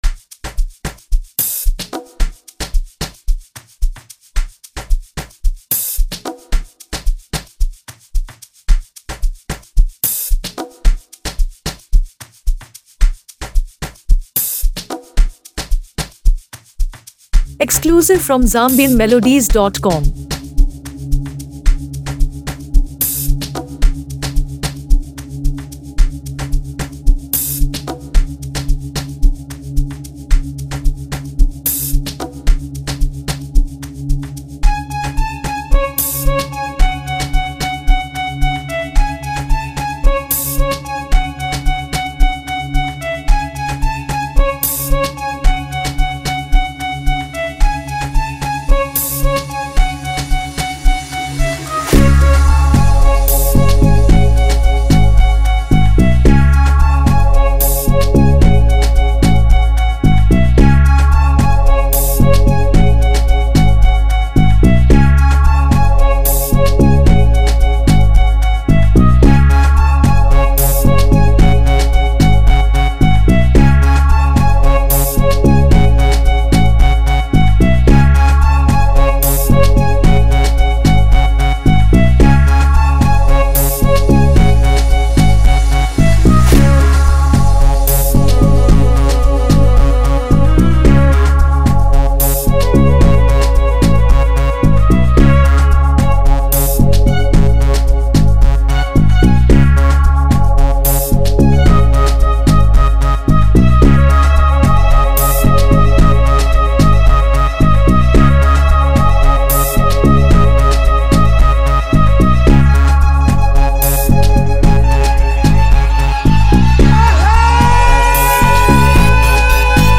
Foreign Music